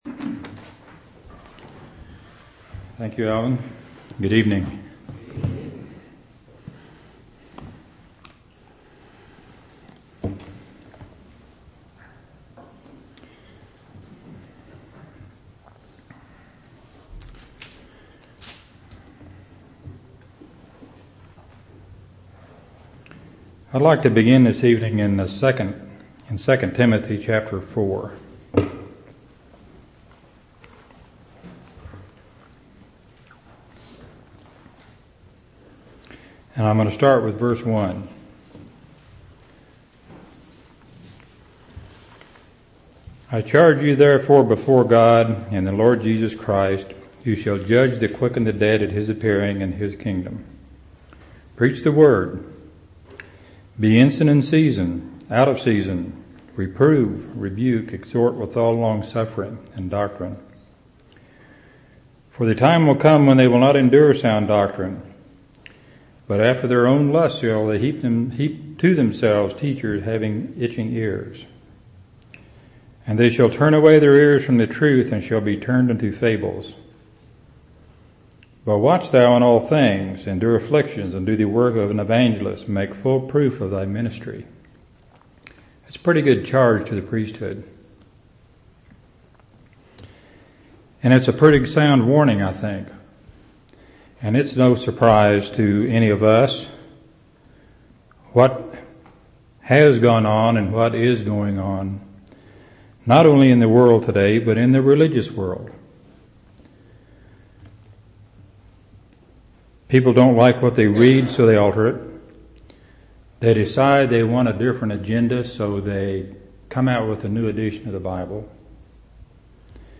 10/5/2003 Location: Temple Lot Local Event